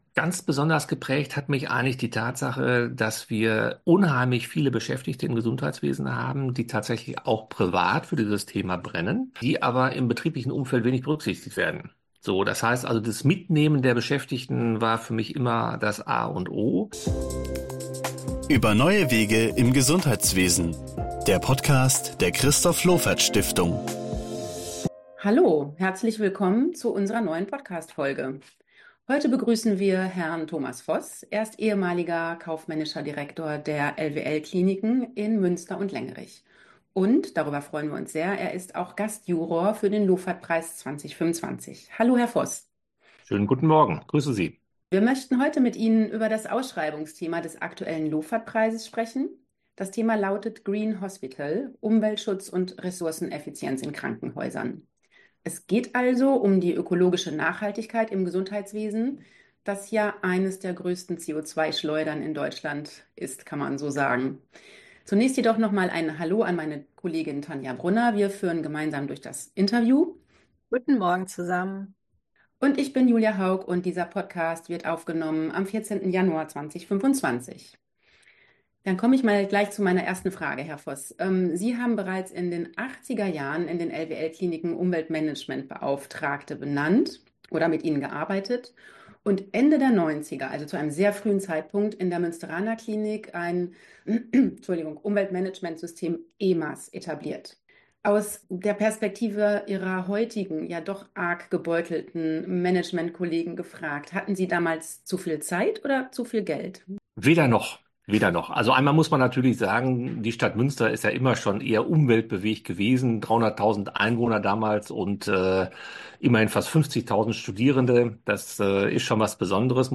Das Interview im Überblick: